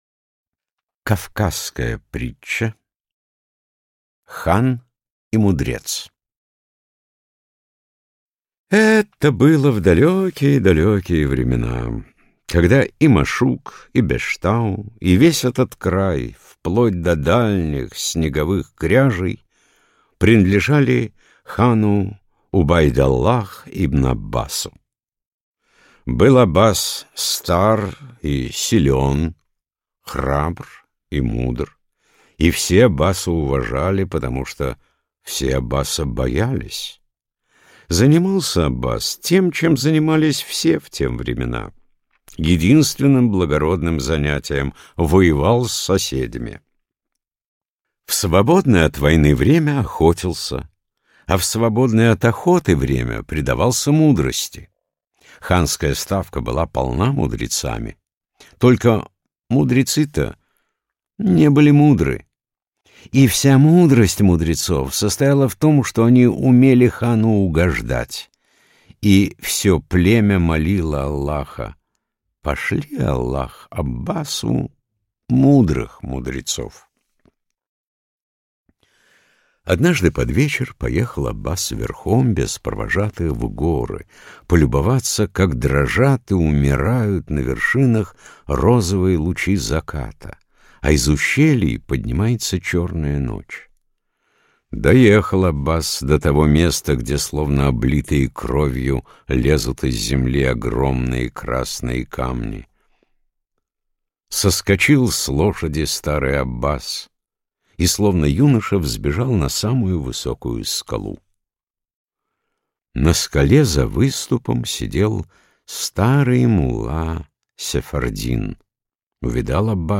Аудиокнига Восточные притчи | Библиотека аудиокниг